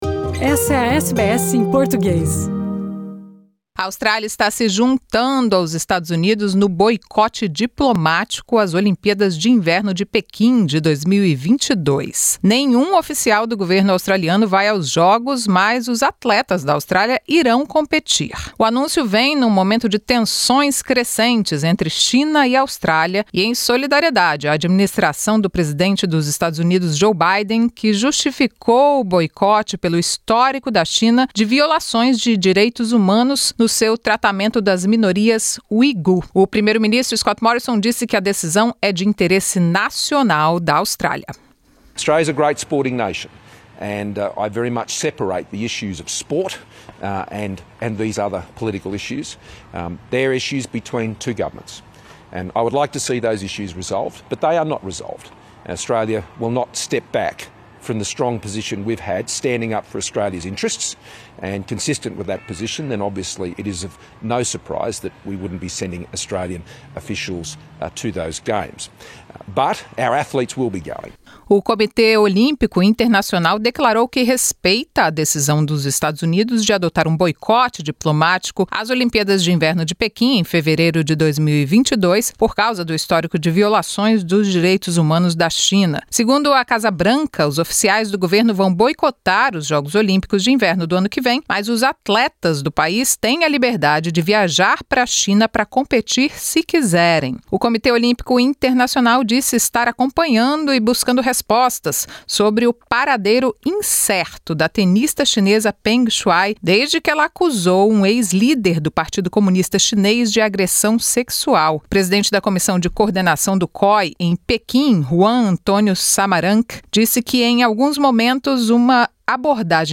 As notícias da Austrália e do mundo da Rádio SBS para esta quarta-feira.